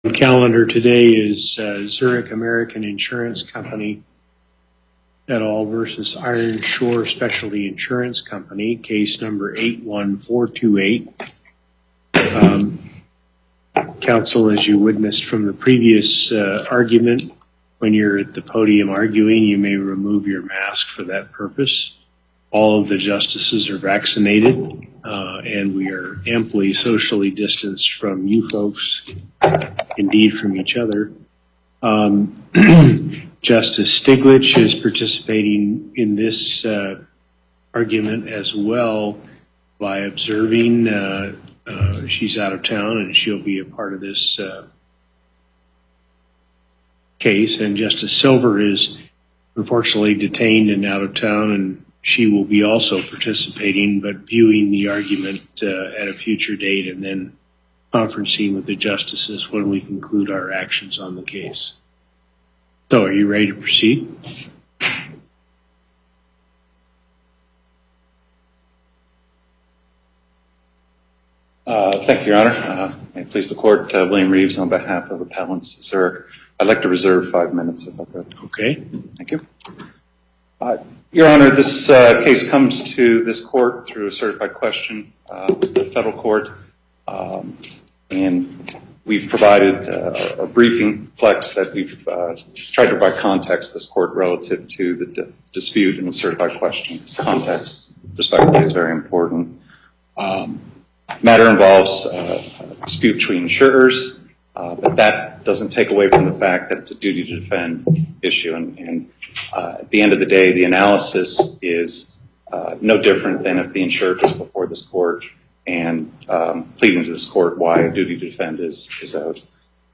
Before the En Banc Court, Chief Justice Hardesty Presiding